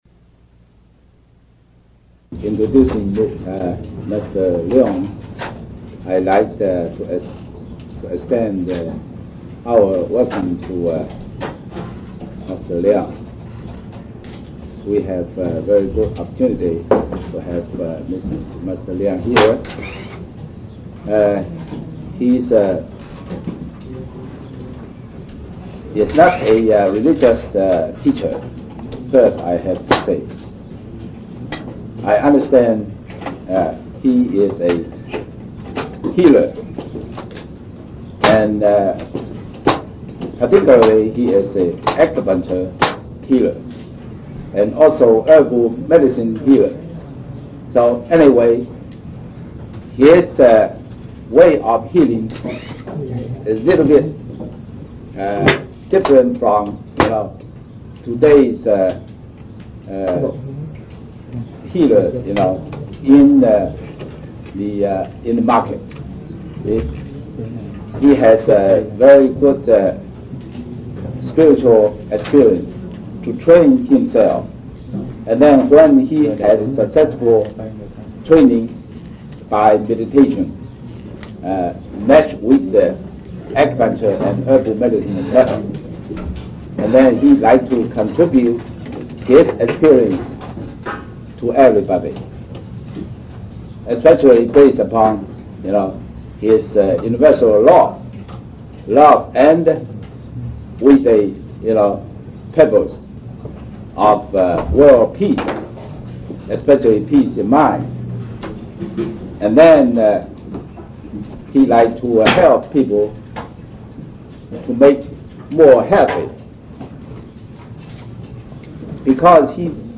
1980 Địa danh : Los Angeles, California, United States Trong dịp : Giảng cho cộng đồng >> wide display >> Downloads